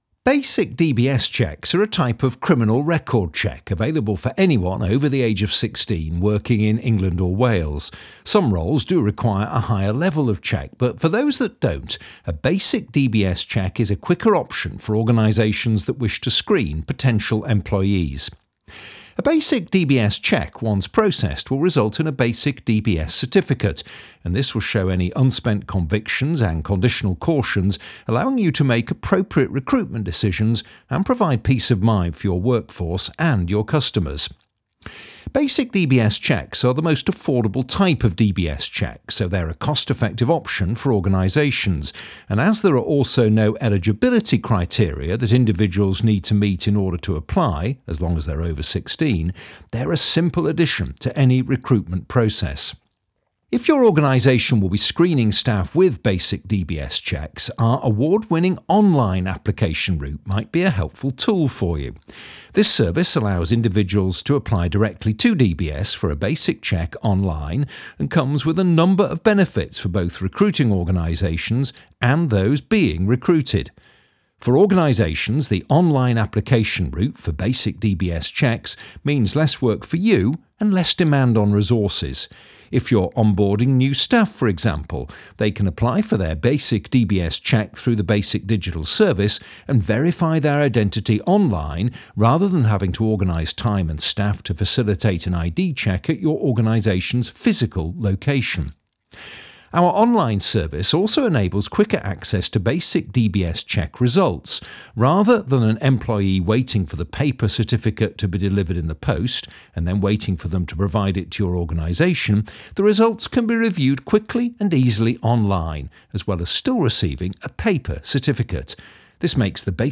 Hire British voice over artists at affordable rates for your next voice recording.
He has an assured vocal delivery, with a clear, professional edge. He is also highly versatile, and has a very memorable tone....